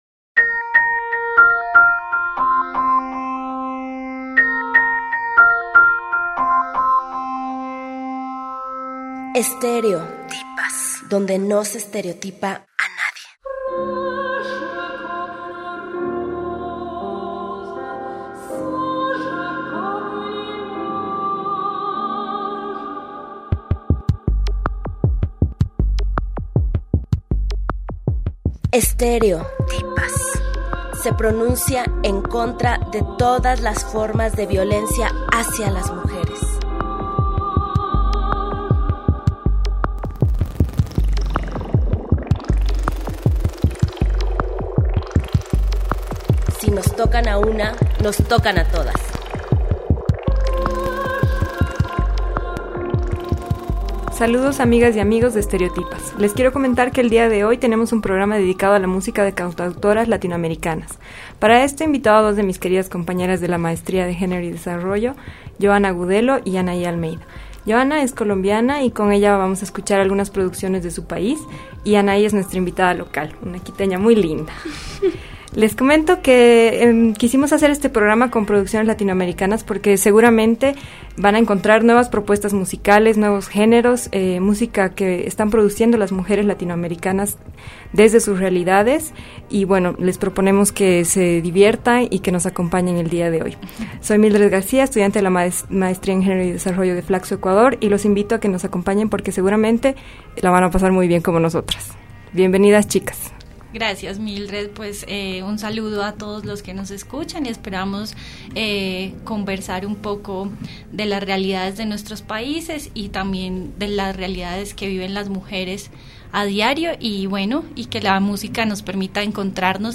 Con una voz muy especial de Bolivia Luzmila Carpio canta a Bartolina Sisa.
estereotipas_musica_latinoamericana.mp3